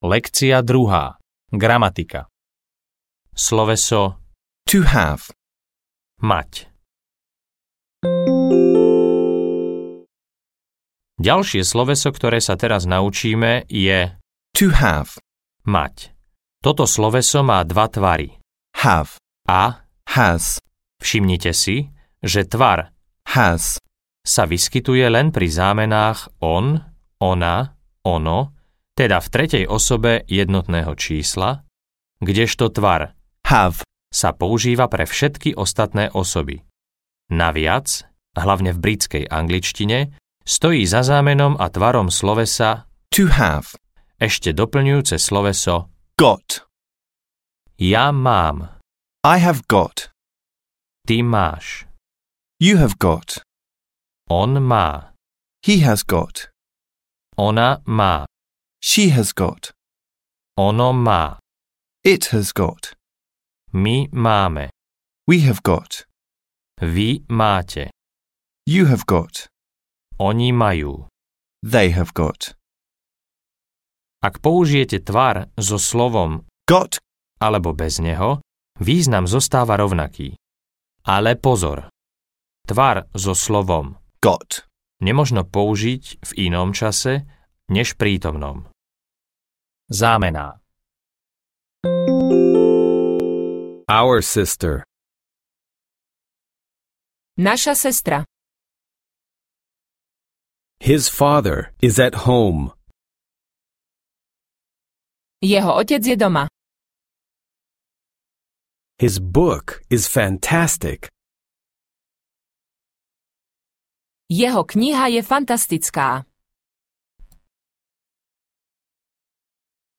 Angličtina do ucha audiokniha